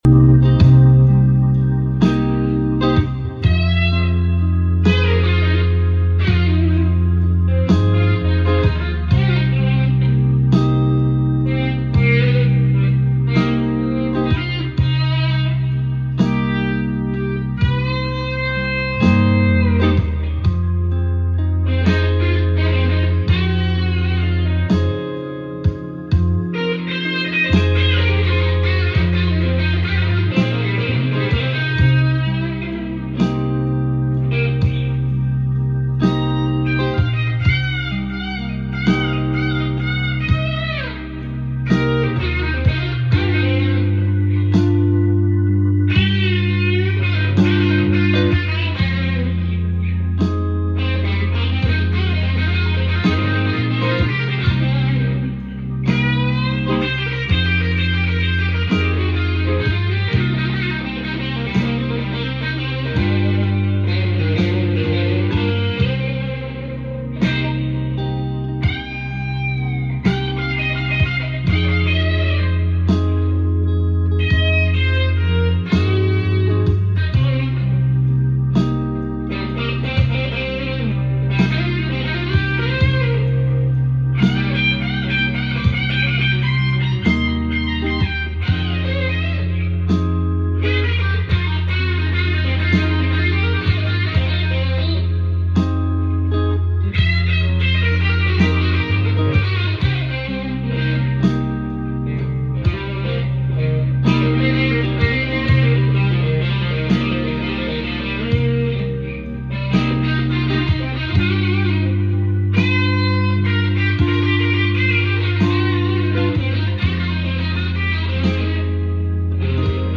Trying out volume swells sound effects free download